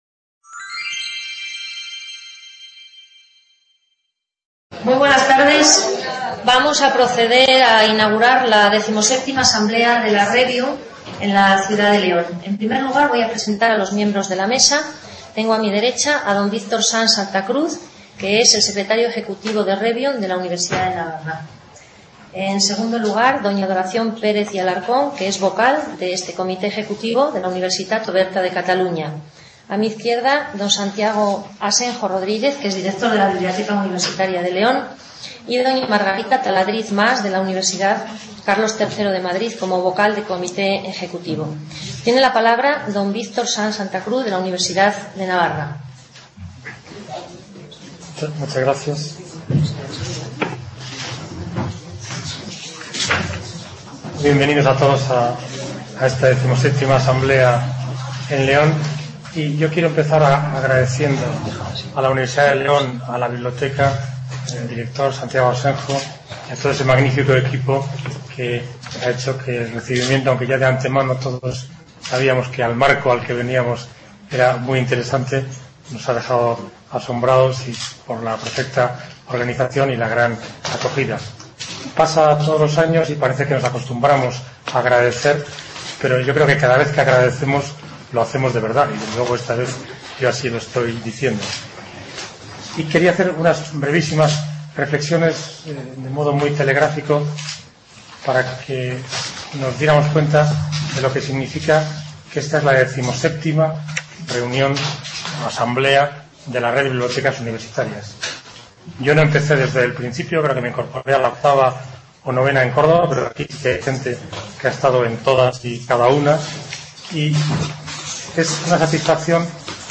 XVII Asamblea CRUE-REBIUN - Acto de inauguración | Repositorio Digital
La Sectorial de Bibliotecas Universitarias CRUE-REBIUN celebrará su XVII Asamblea Anual, siendo anfitriona la Universidad de León, los días 4, 5, 6 de noviembre de 2009. Desde la Biblioteca de la ULE pretendemos retransmitir por videoconferencia estas sesiones que consideramos de interés para el colectivo universitario.